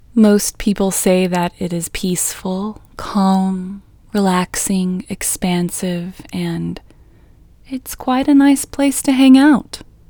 QUIETNESS Female English 17
Quietness-Female-17-1.mp3